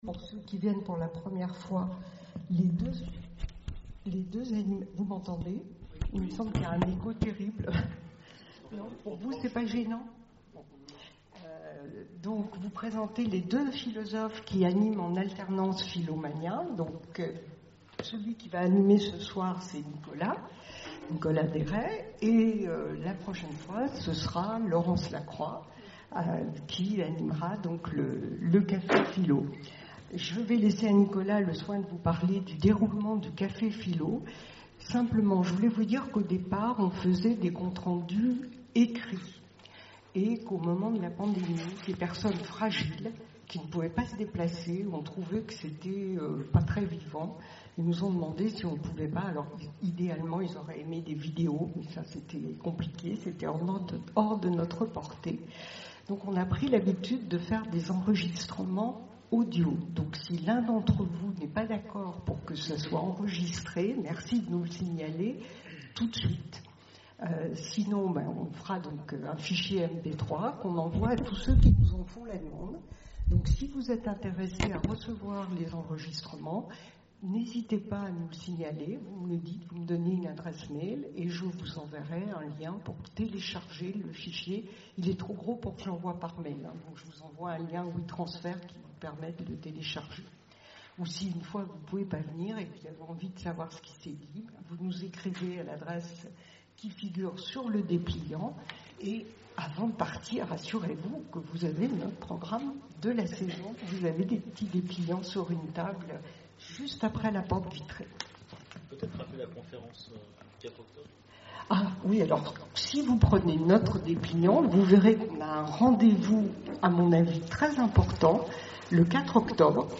Conférences et cafés-philo, Orléans
CAFÉ-PHILO PHILOMANIA Peut-on échapper au conflit ?